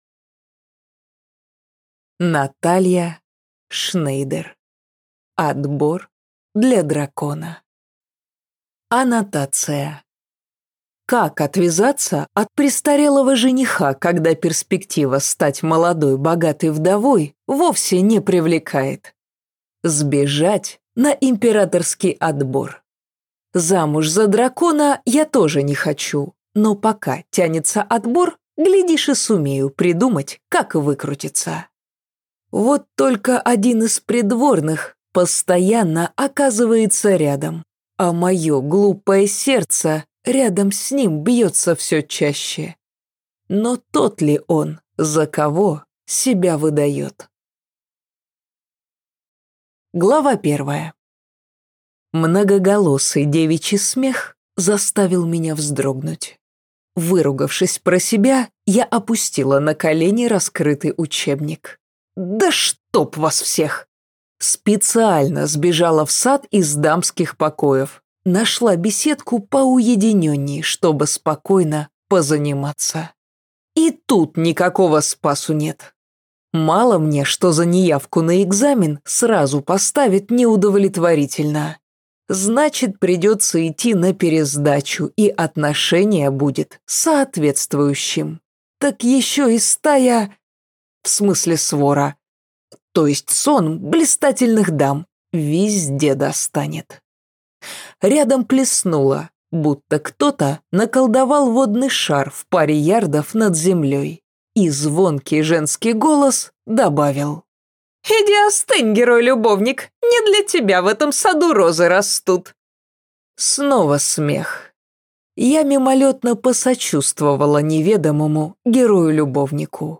Аудиокнига Отбор для дракона | Библиотека аудиокниг